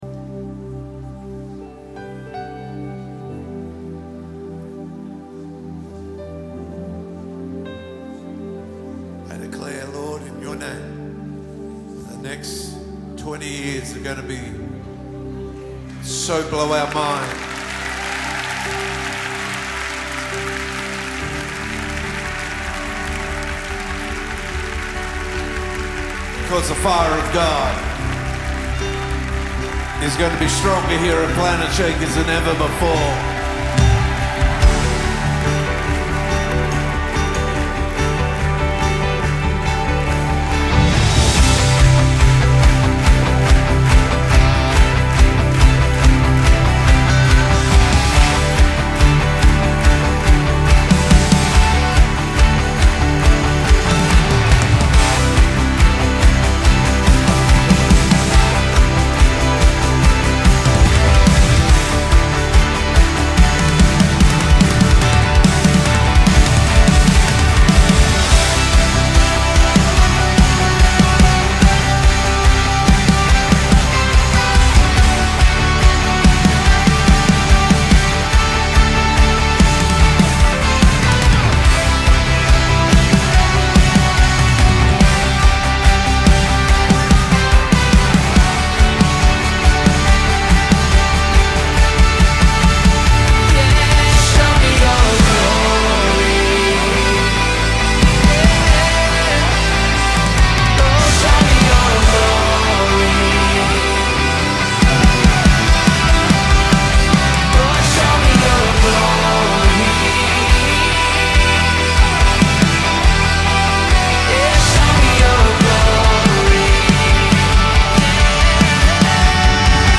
песня
C  Em  F  D